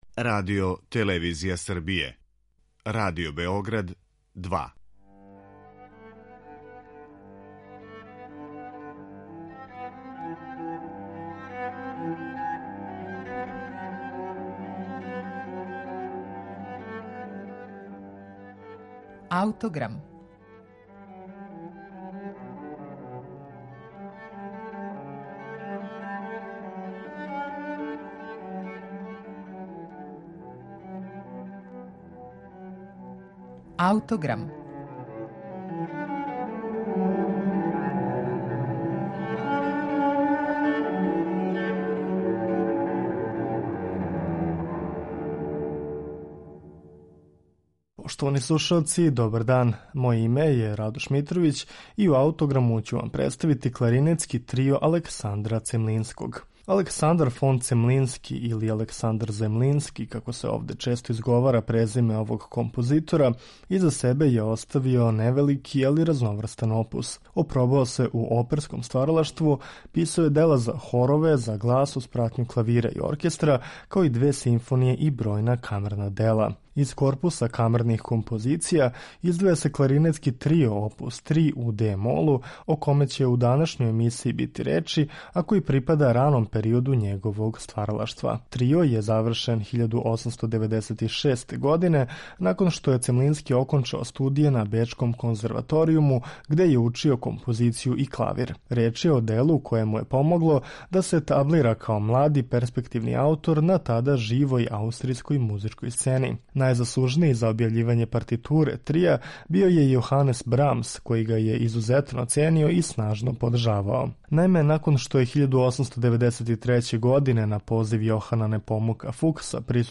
Кларинетски трио опус 3 у де-молу Александра Цемлинског припада раном периоду стваралаштва овог свестраног композитора.
Кларинетски трио Александра Цемлинског слушаћете у извођењу трија Beaux Arts.